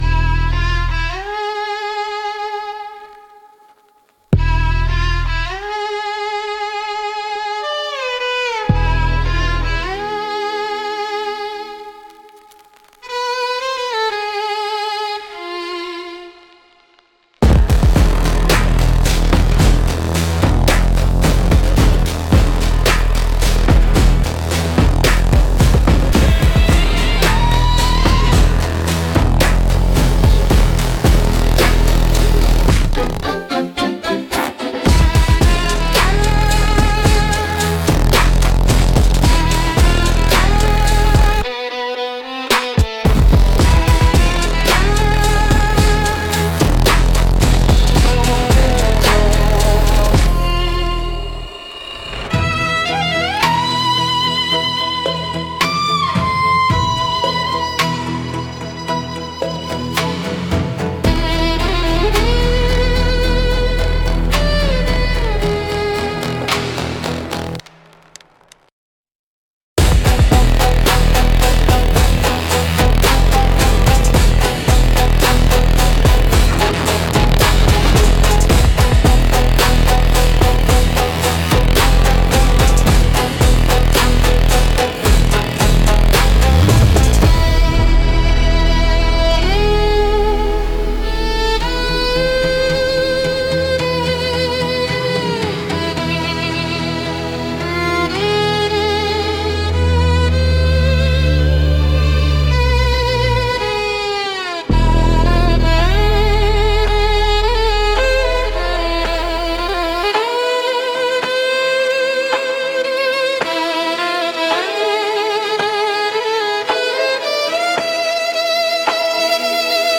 Instrumental - Cinematic Trap x Dark Soul Electronic 3.16